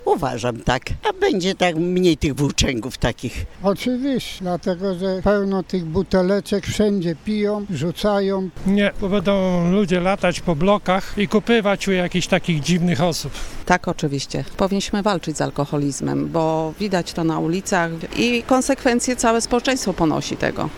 Zapytaliśmy mieszkańców Stargardu, czy uważają, że to rozwiązanie powinno być też wprowadzone w mieście.